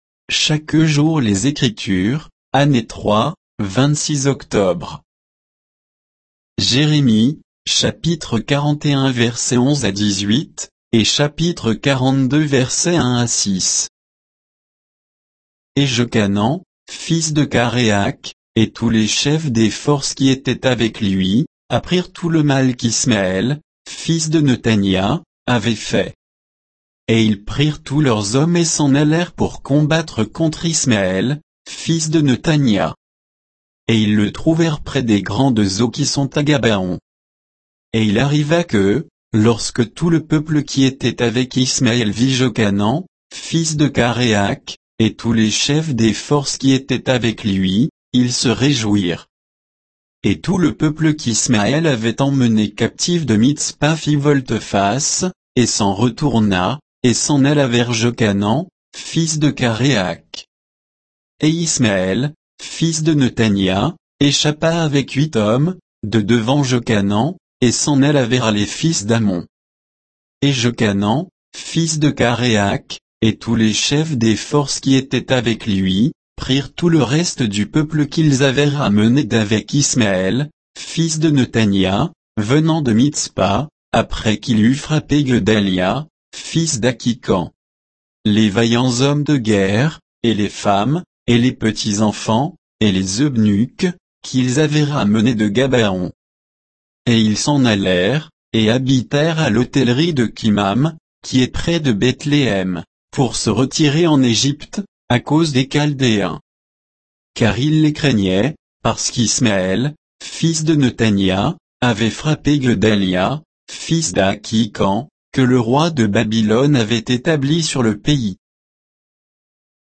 Méditation quoditienne de Chaque jour les Écritures sur Jérémie 41